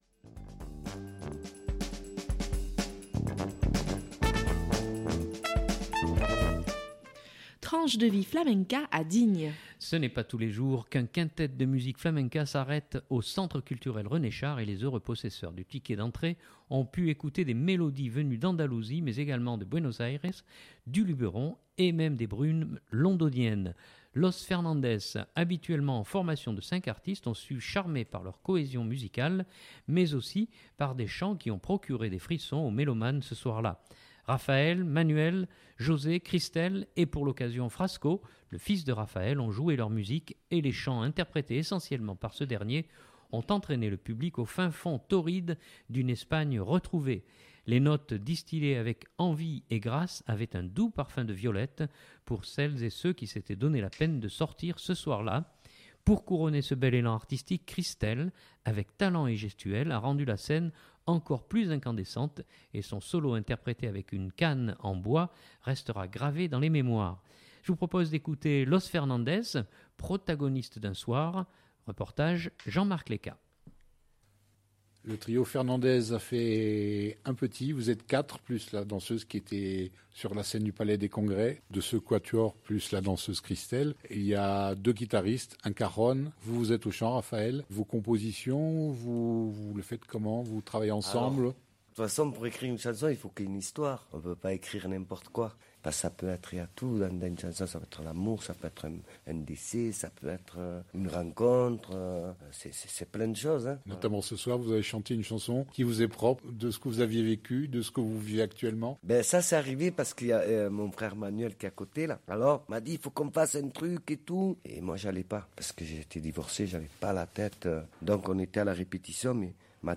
Ce n’est pas tous les jours qu’un quintet de musique flamenca s’arrête au Centre Culturel René Char et les heureux possesseurs du ticket d’entrée on pu écouter des mélodies venues d’Andalousie mais également de Buenos Aires, du Luberon même des brumes londoniennes !
reportage